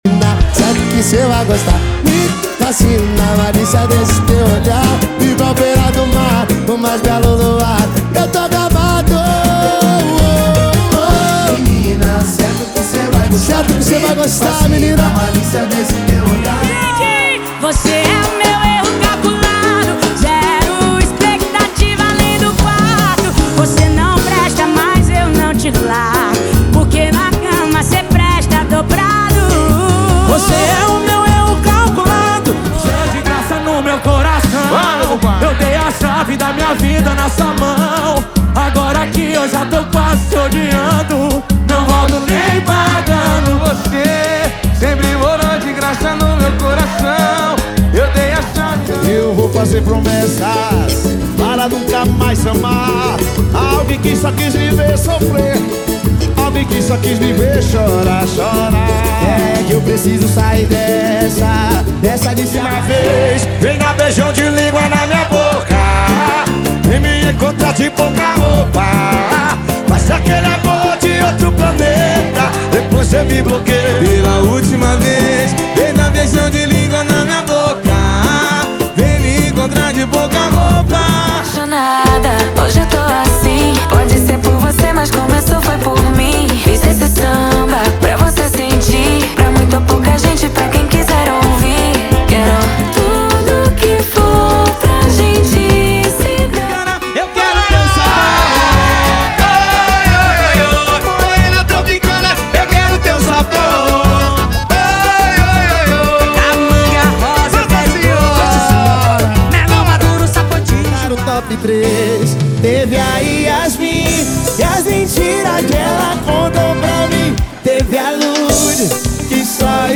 • Sem Vinhetas
• Em Alta Qualidade